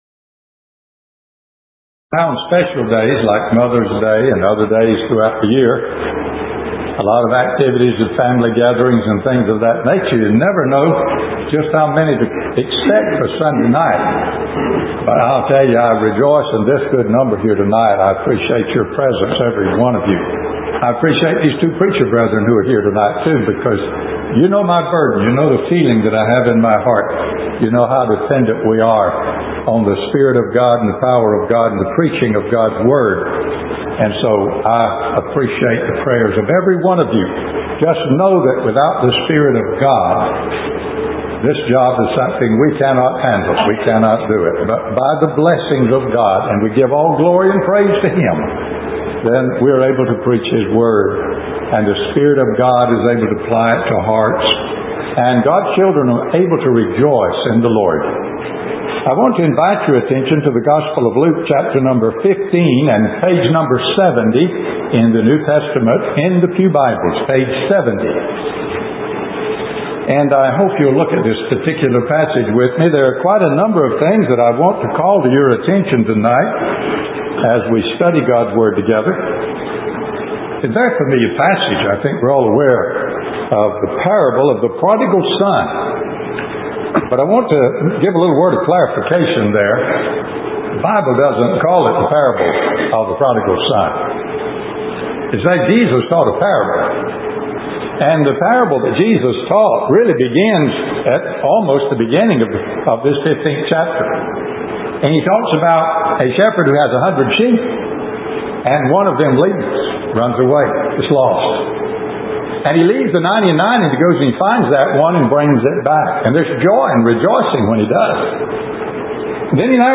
Luke 15:13, Righteous or Riotous Living Nov 5 In: Sermon by Speaker